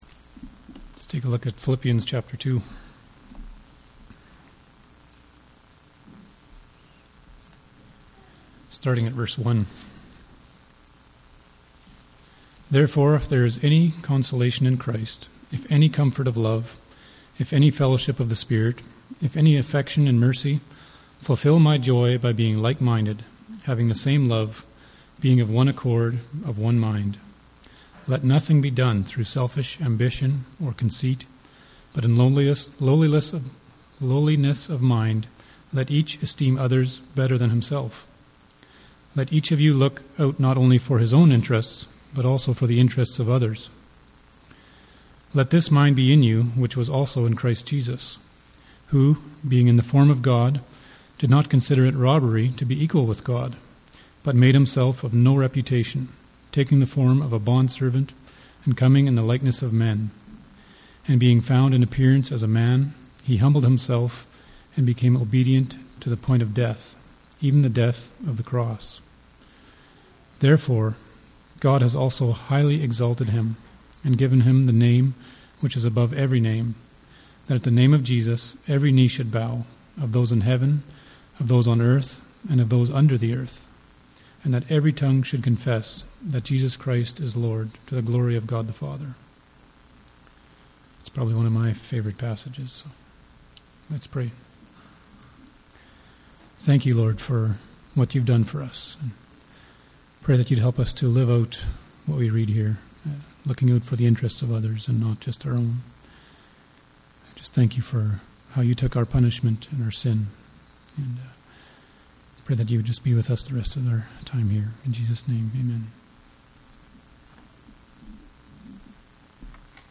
Communion Service
Communion Service Type: Sunday Morning « Influencing Our Culture